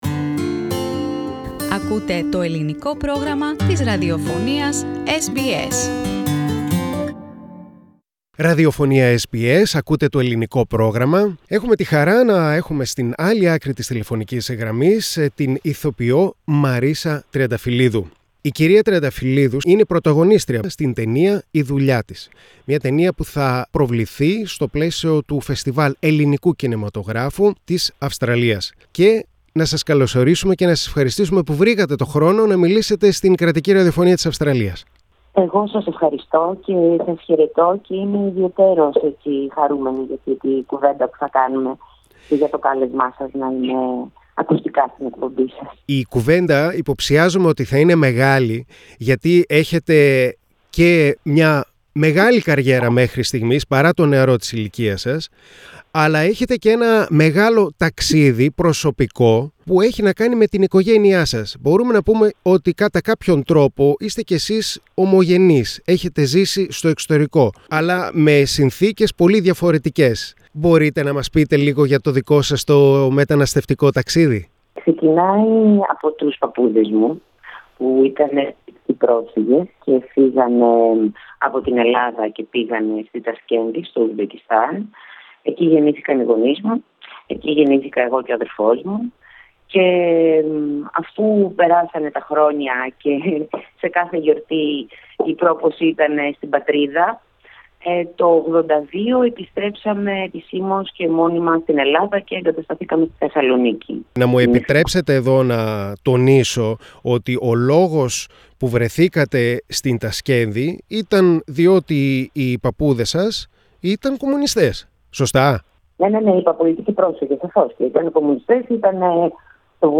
Greek actress Marisha Triantafyllidou in "Her Job" film plays 37-year-old Panayiota and creates an intuitive portrait of a Greek woman, who lives in a harsh family environment and she gains her freedom when she finds herself a job. Ms Triantafyllidou talks to SBS Greek about the role of Panayiota and her family's migration journey.